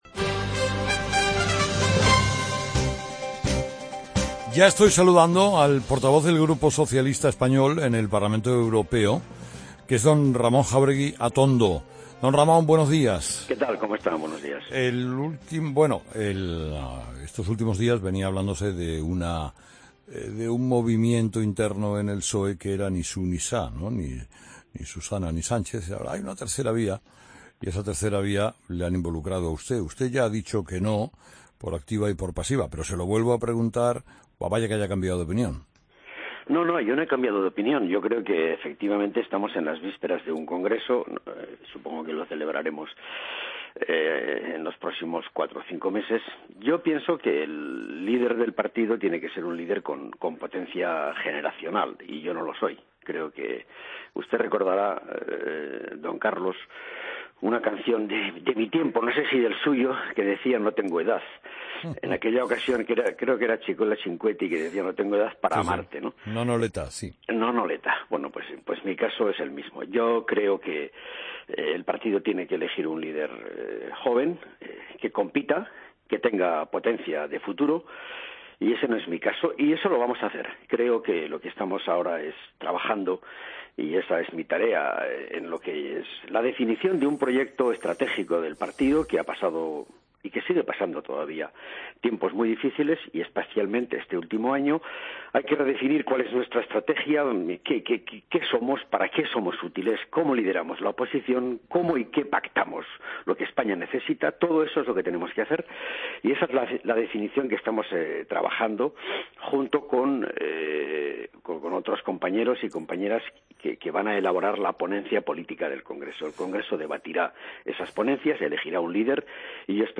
Escucha la entrevista a Ramón Jáuregui, portavoz del PSOE en el Parlamento Europeo, en 'Herrera en COPE'